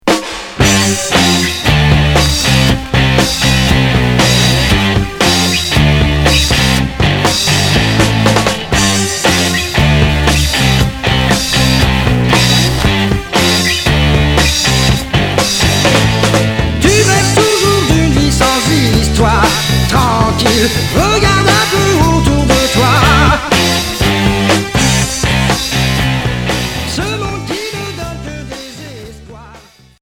Heavy rock Quatrième 45t retour à l'accueil